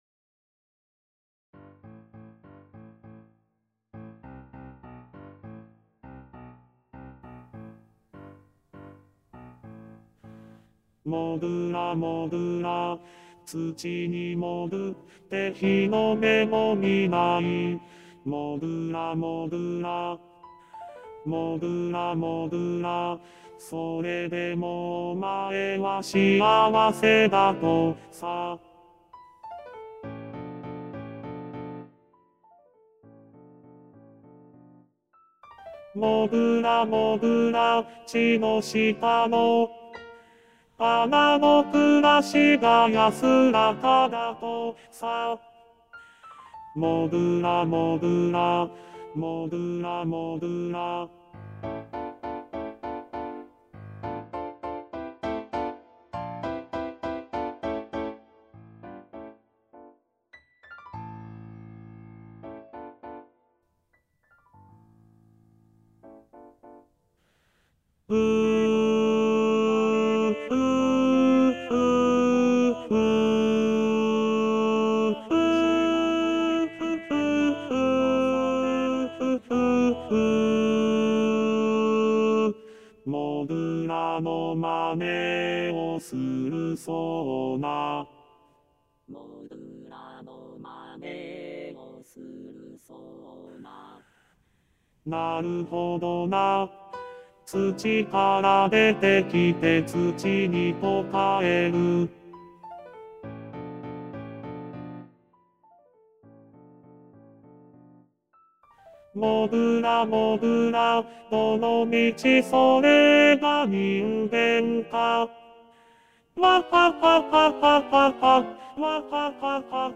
★第１２回定期演奏会　演奏曲　パート別音取り用 　機械音声(ピアノ伴奏希望はｽｺｱｰﾌﾟﾚｱｰsdxで練習して下さい)
第四楽章 もぐらもち【TenorⅡ】(修正版).mp3